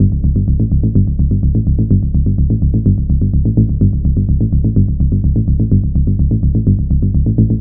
BL 126-BPM 2-G.wav